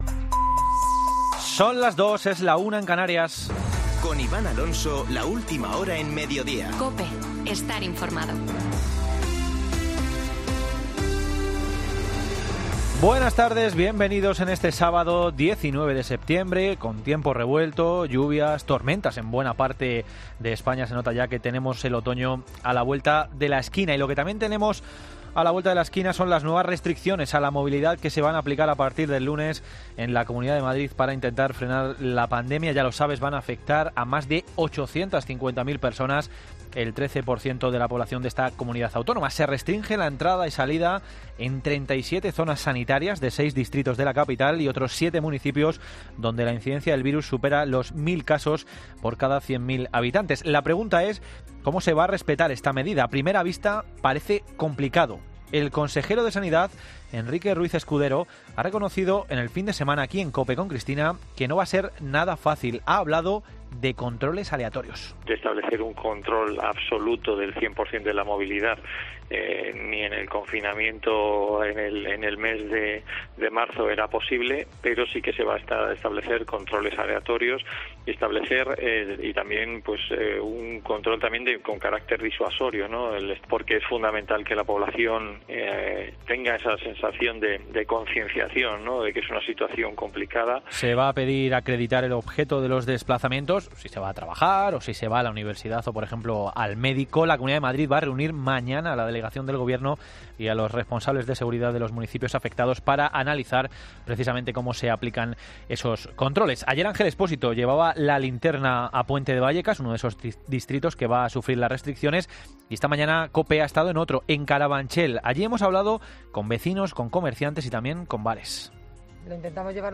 Boletín de noticias de COPE del 19 de septiembre de 2020 a las 14.00 horas